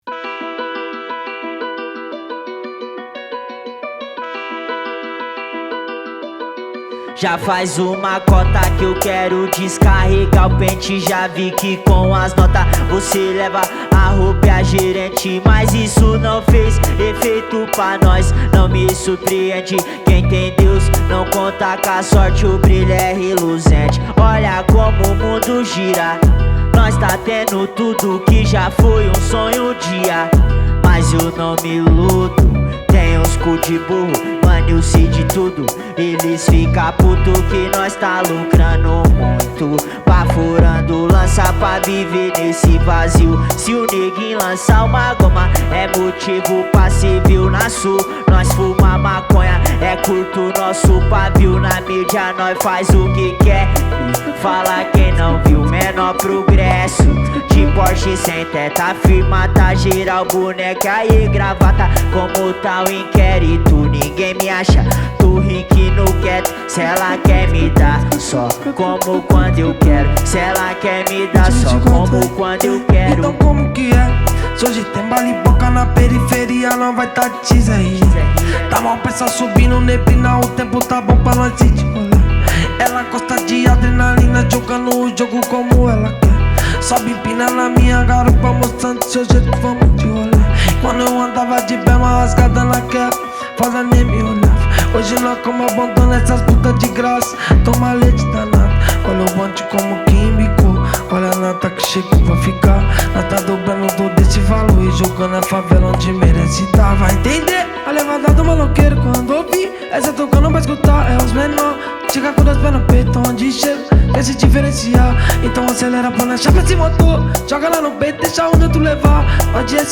2024-06-06 16:20:49 Gênero: Funk Views